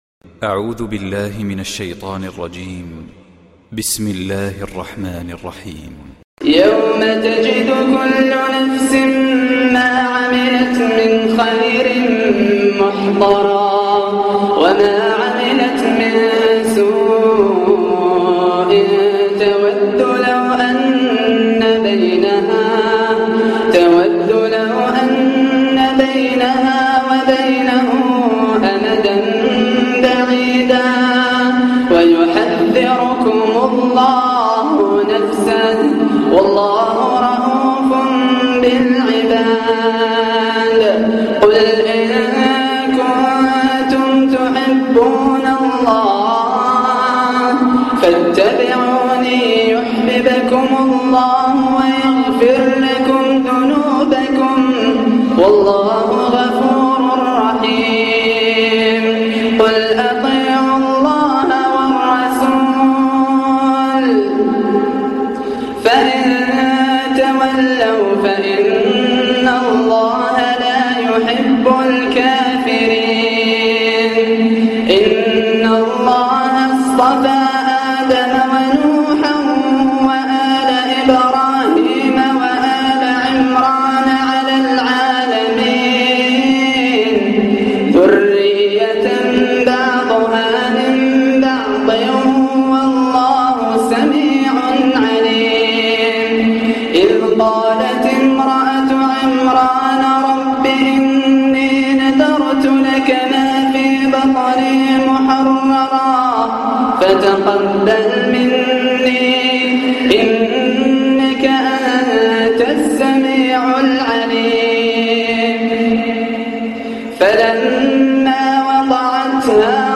اروع تلاوه خاشعة مؤثرة جداً من الجزائر من سورة آل عمران